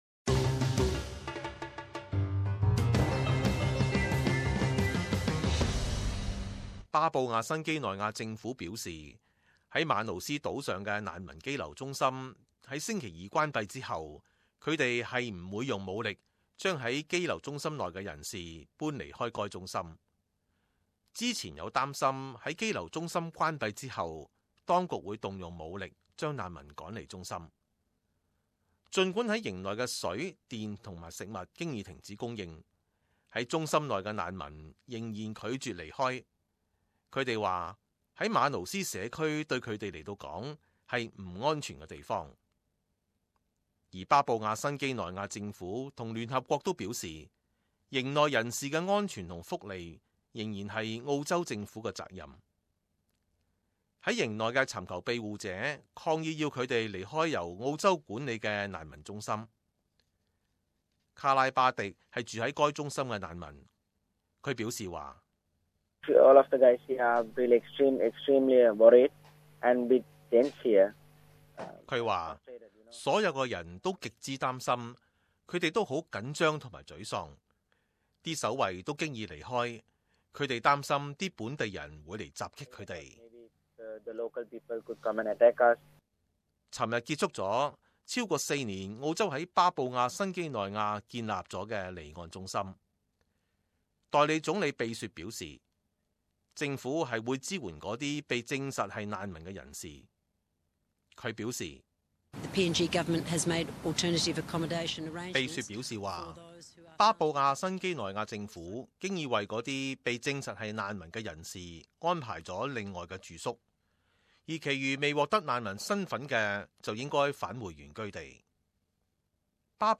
【時事報導】難民堅決留守馬魯斯羈留中心